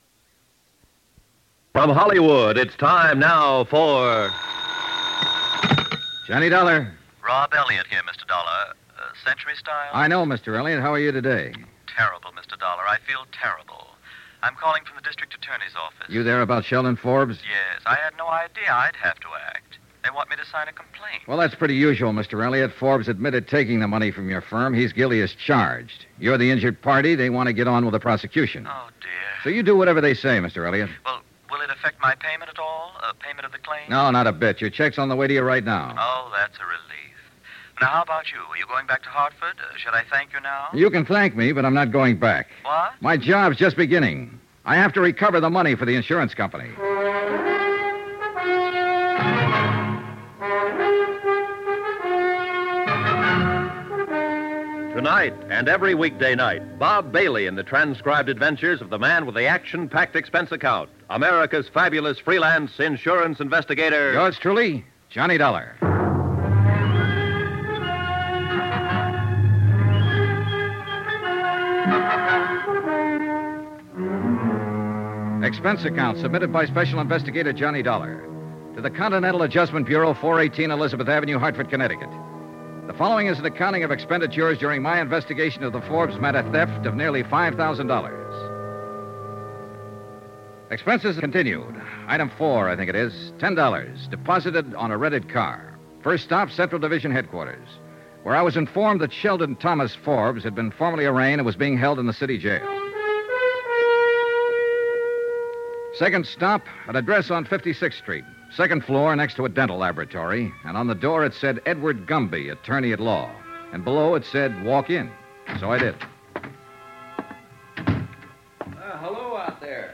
Yours Truly, Johnny Dollar Radio Program, Starring Bob Bailey